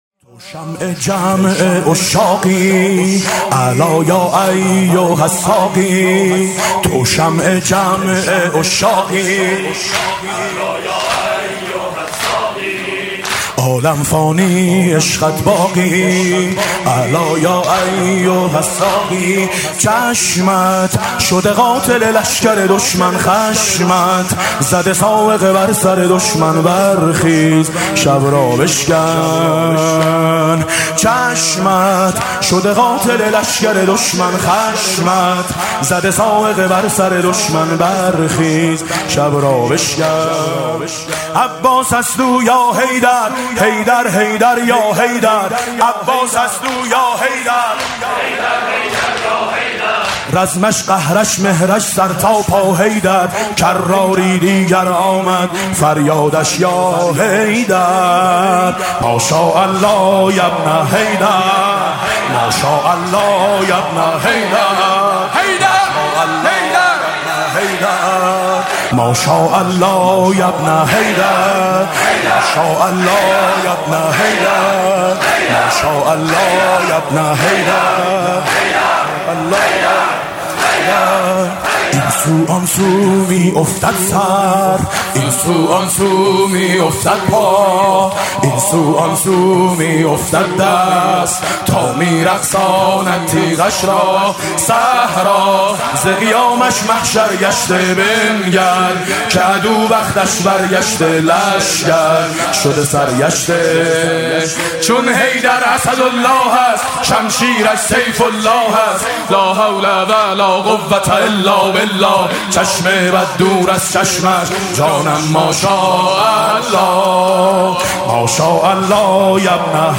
مداحی حاج میثم مطیعی دهه اول محرم 1400 هیئت آیین حسینی این پست در شب های دهه اول ماه محرم بروزرسانی می گردد.